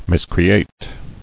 [mis·cre·ate]